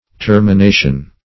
Termination \Ter`mi*na"tion\, n. [L. terminatio a bounding,